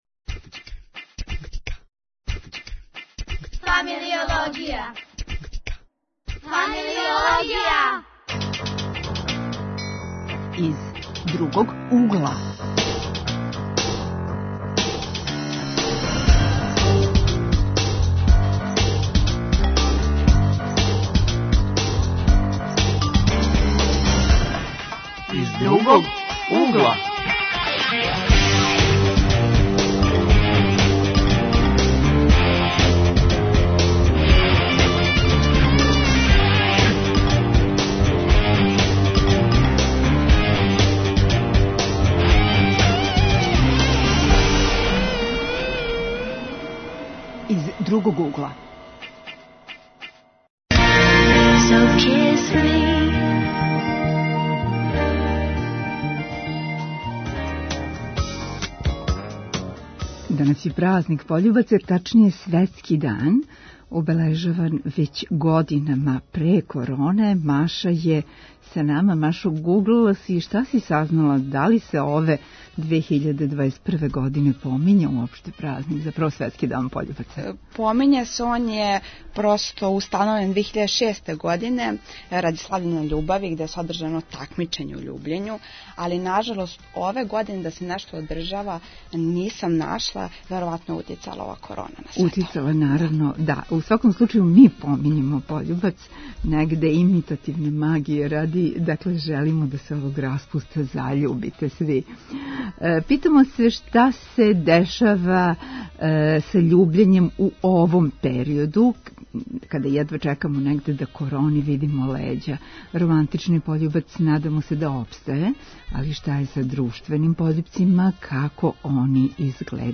Из енциклопедијског, научног, песничког, и из угла гугла... гости у студију су студенти.